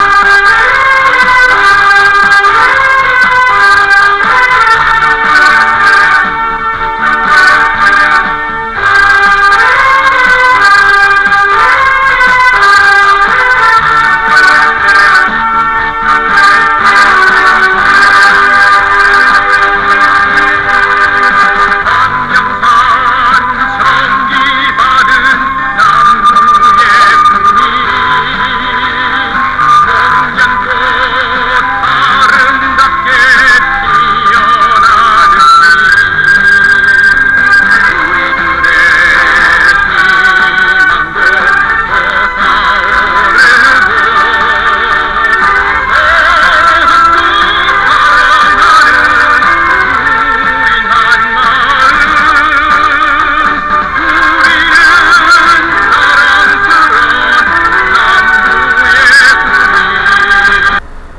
조금빠르게 Moderato